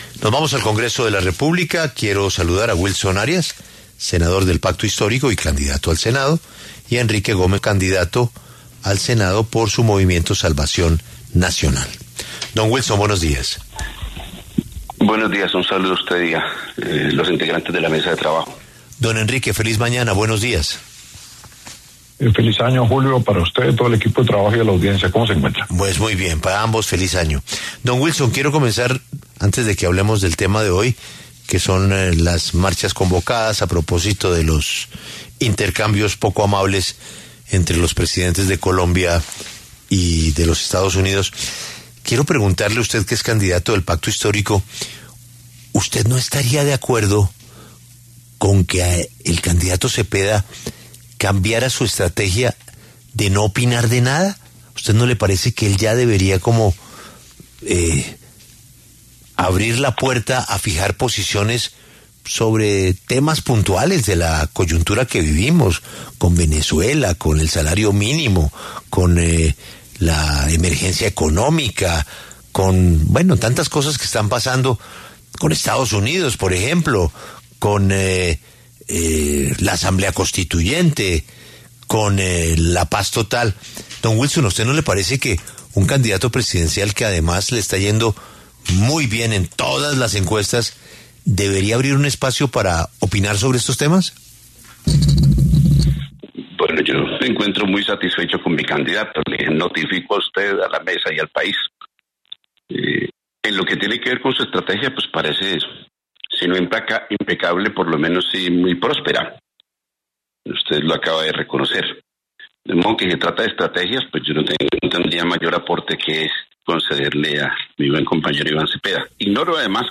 debaten en La W
Wilson Arias, senador del Pacto Histórico y candidato al Senado